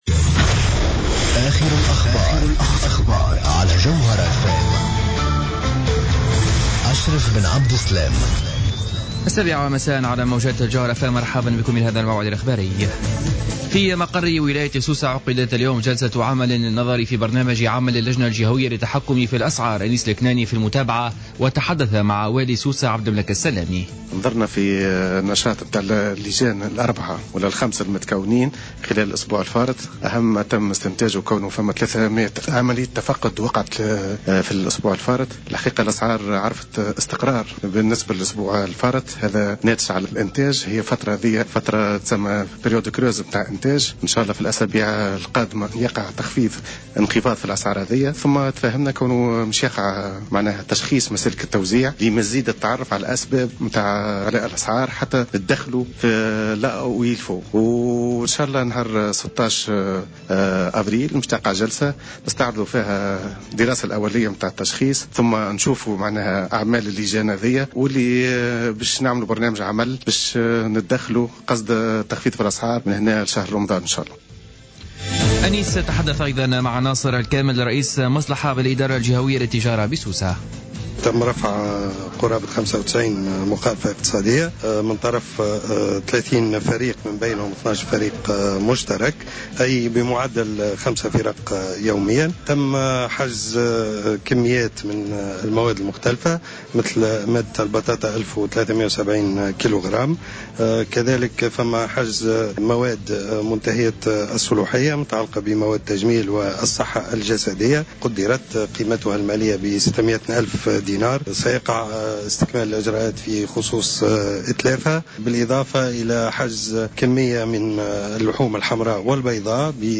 نشرة أخبار السابعة مساء ليوم الخميس 2 أفريل 2015